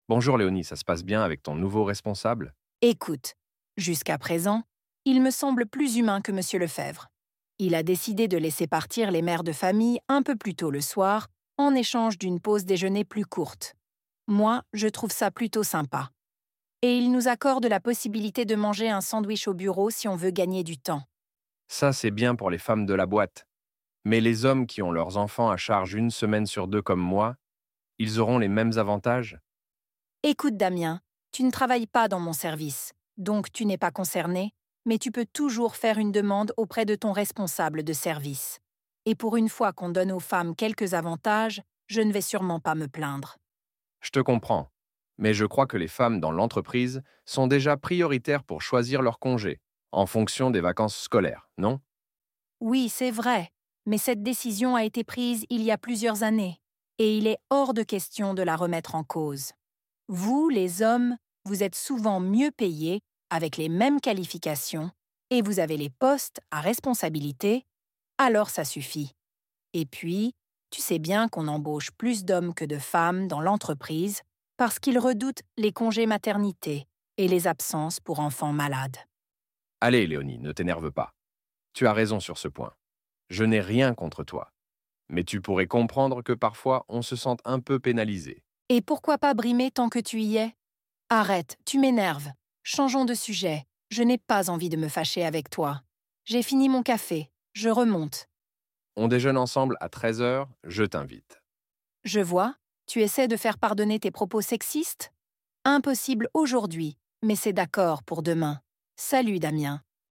Dialogue – Inégalités au travail (Niveau B1)